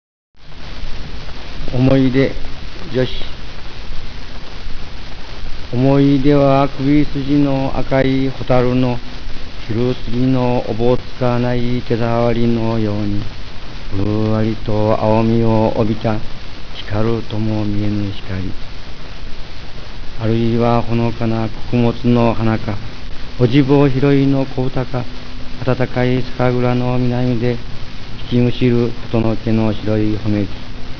えっ！北原白秋の肉声が残っていた！！